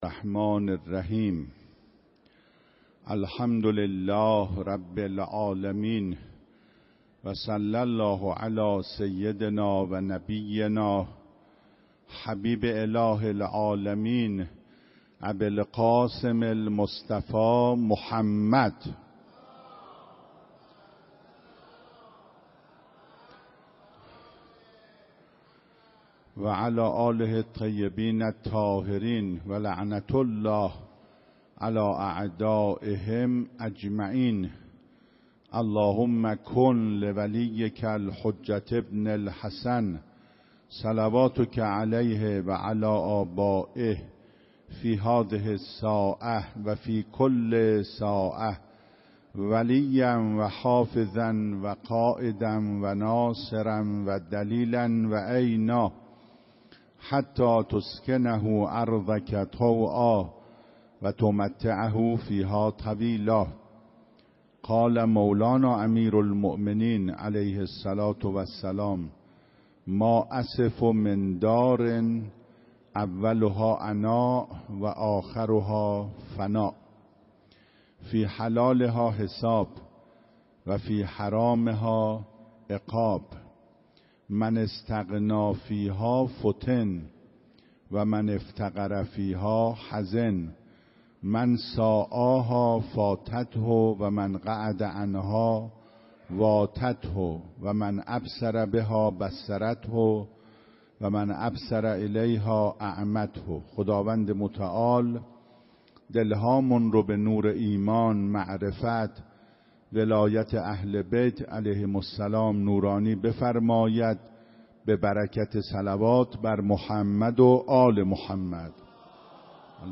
16 رمضان 96 - حرم حضرت معصومه - سخنرانی